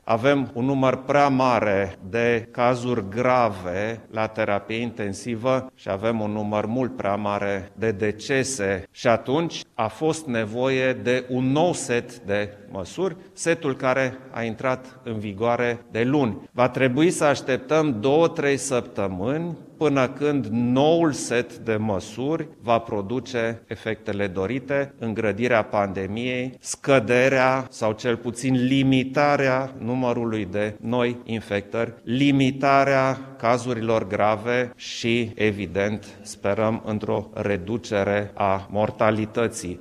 Preşedintele Klaus Iohannis a făcut din nou apel către populaţie să respecte noile restricţii, impuse pentru a limita răspândirea noului coronavirus. Şeful statului crede că aceste măsuri suplimentare vor avea rezultate în două sau trei săptămâni: